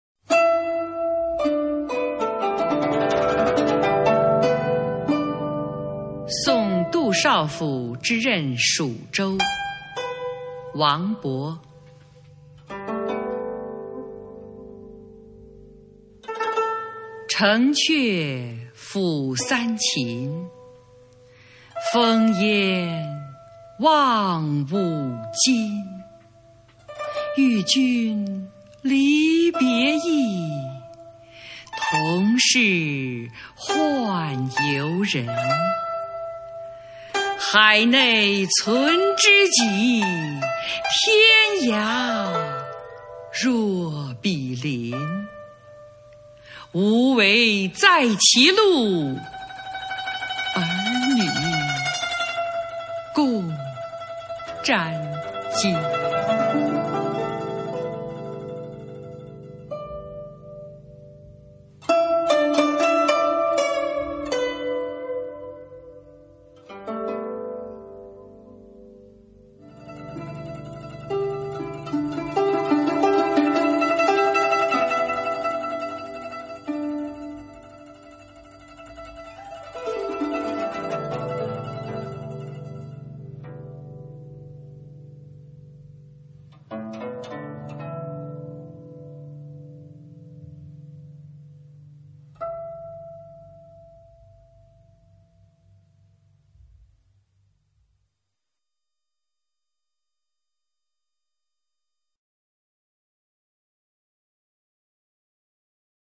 首页 视听 经典朗诵欣赏 群星璀璨：中国古诗词标准朗读（41首）